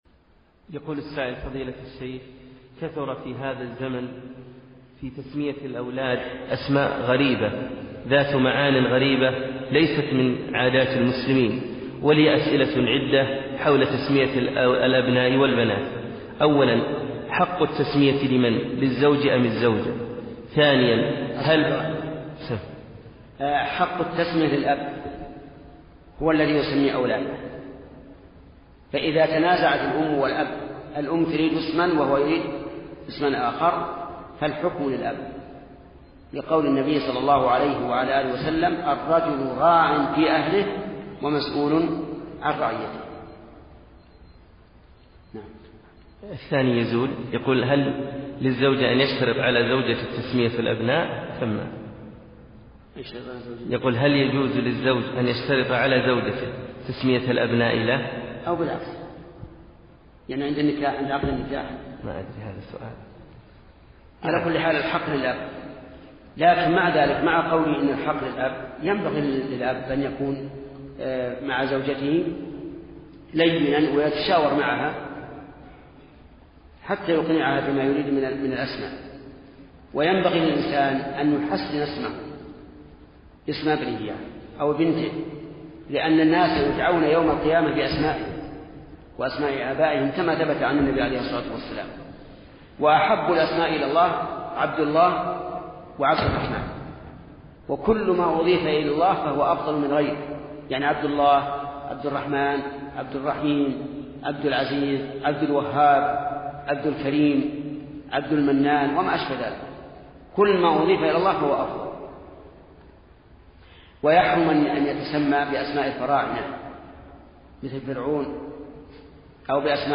Scheich Muhammad ibn Uthaimin, rahimahullah, sagte:
Audioaufnahme des Schaykhs aus اللقاء الشهري, Nr 28.